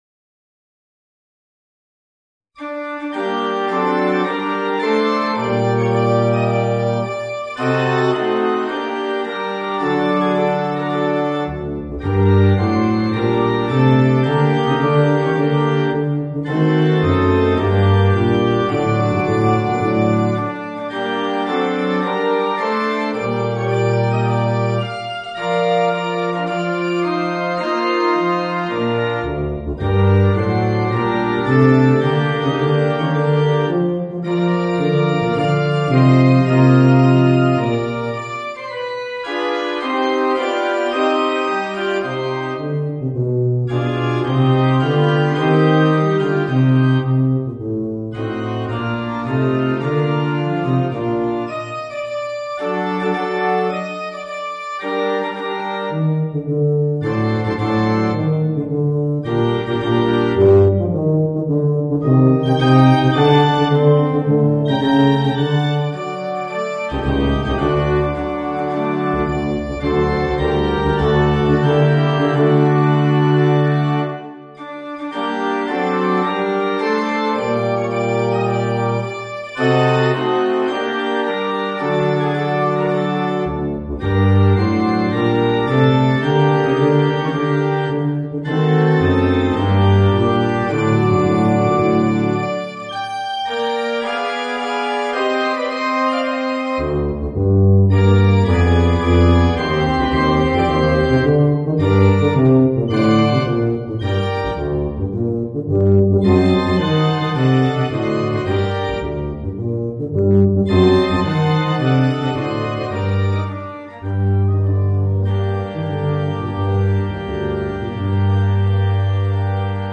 Voicing: Bb Bass and Organ